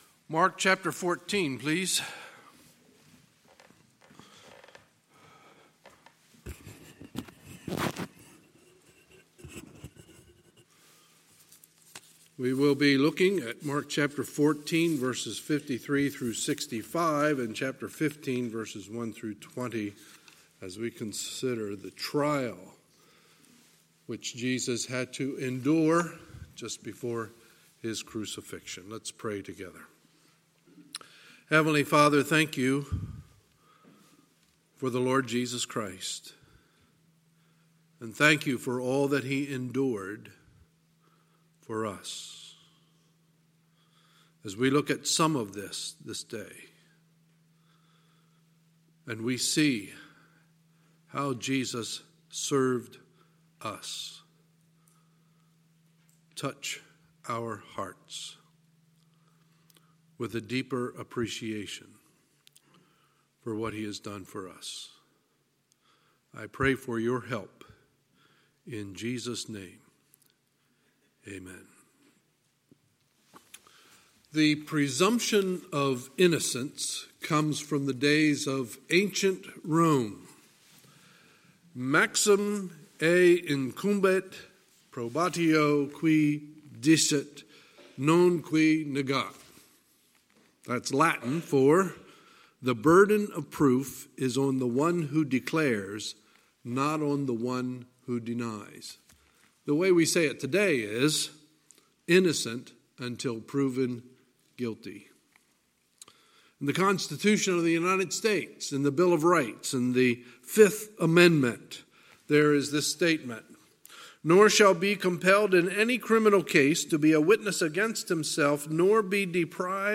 Sunday, November 3, 2019 – Sunday Morning Service